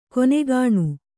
♪ konegāṇu